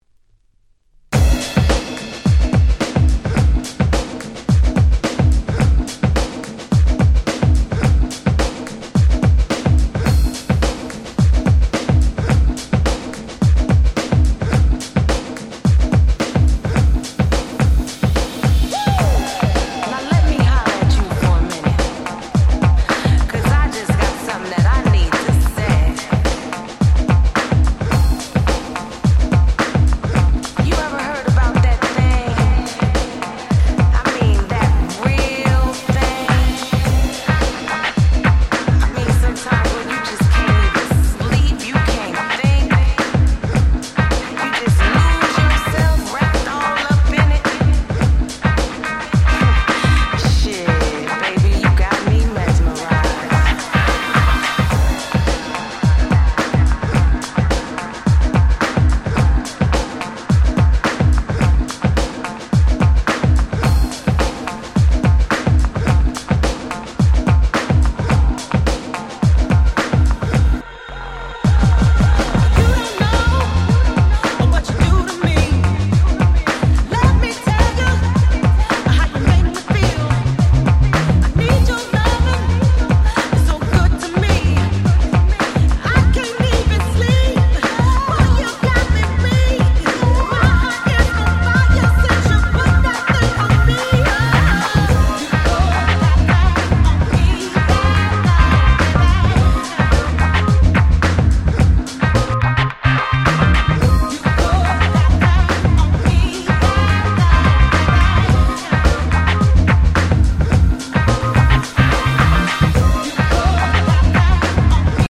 07' Nice Reggae Remix / Re-Edit !!